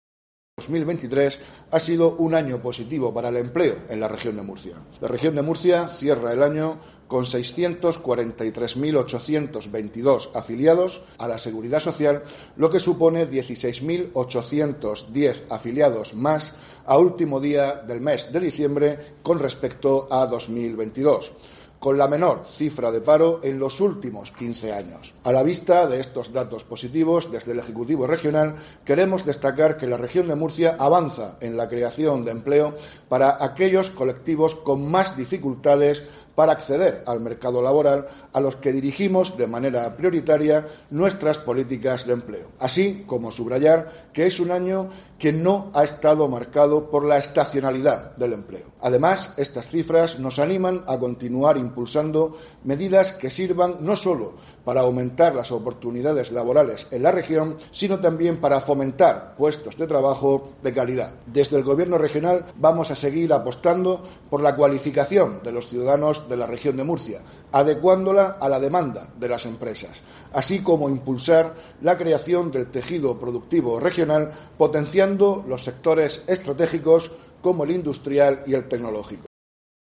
Víctor Marín, consejero de Educación, Formación Profesional y Empleo